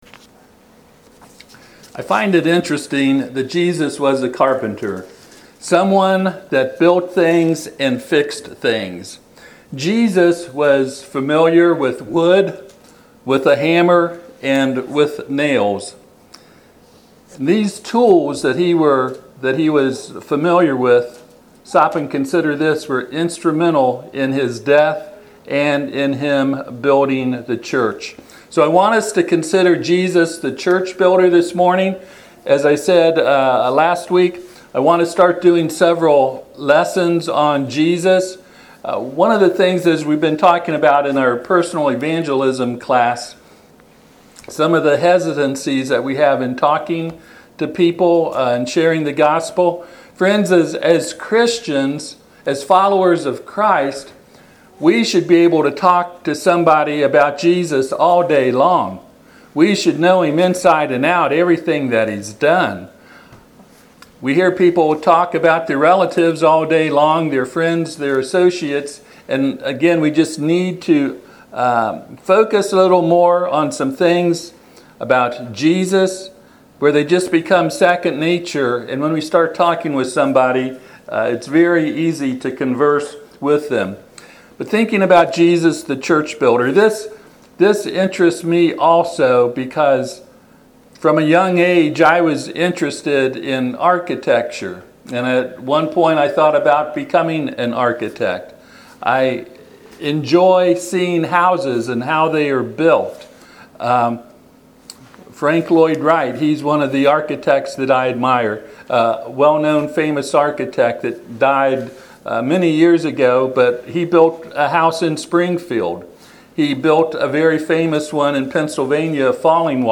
Matthew 16:13-18 Service Type: Sunday AM Topics